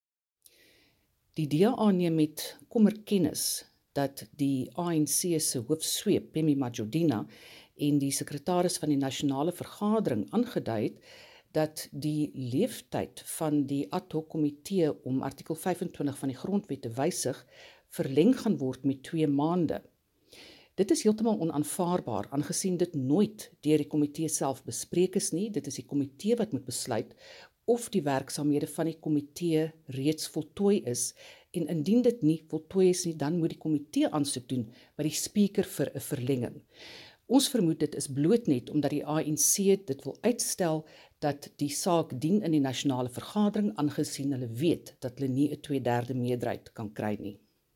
Afrikaans soundbites by Dr Annelie Lotriet MP.